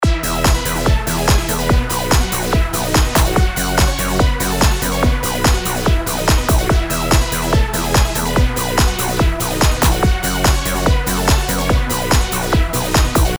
das ist eigentlich die typische Eurodance Bassline und gibt auch mehr Drive.